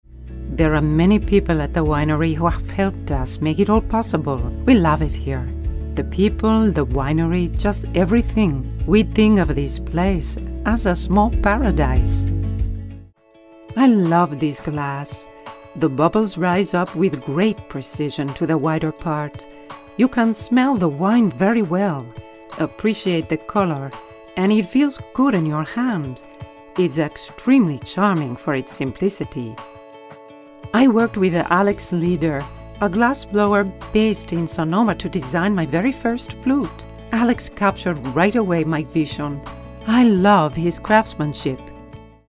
Female
Narration
Spanish Accented Documentary
All our voice actors have professional broadcast quality recording studios.